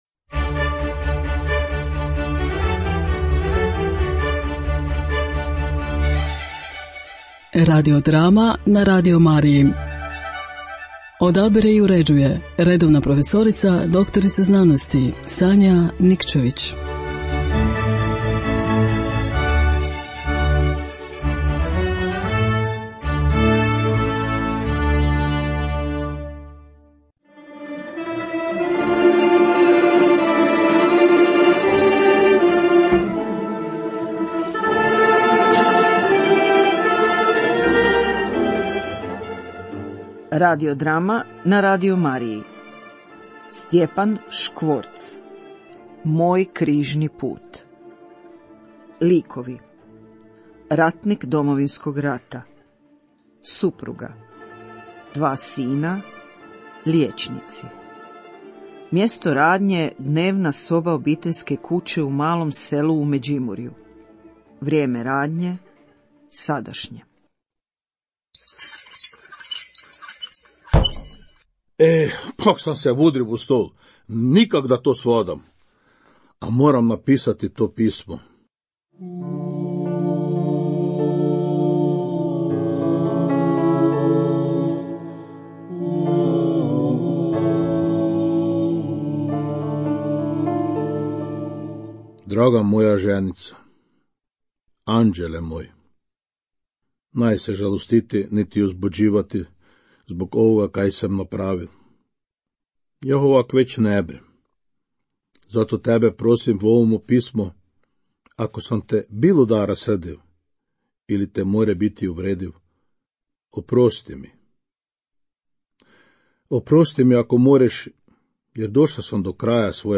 Radio drame na Radio Mariji